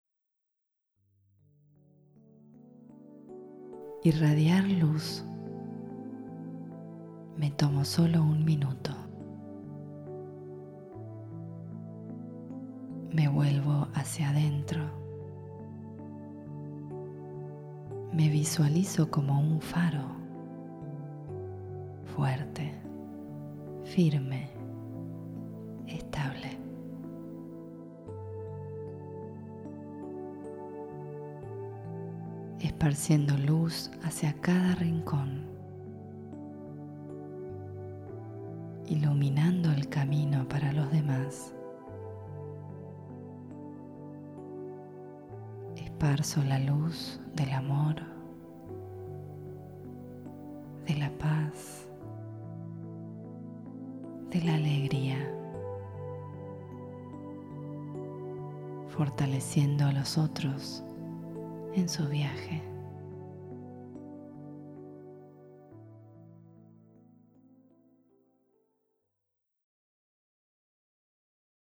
Genre Meditaciones Guiadas